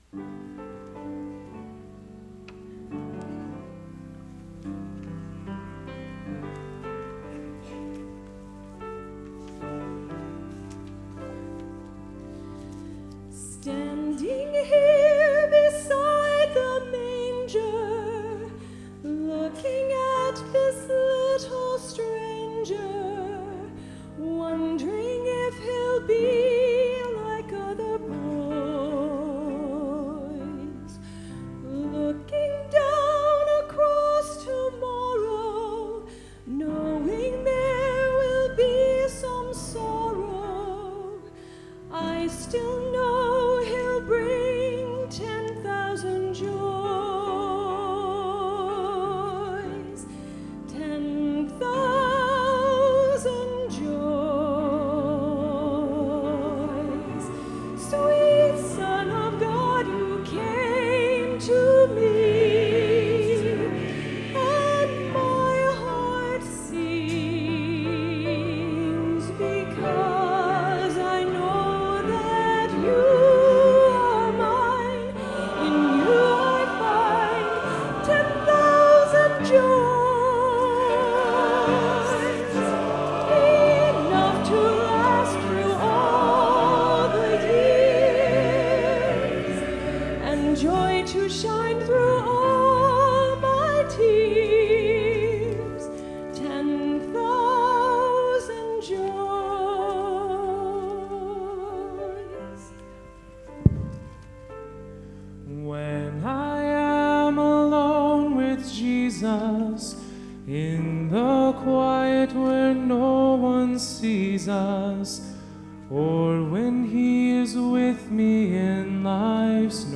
“Ten Thousand Joys” ~ Choir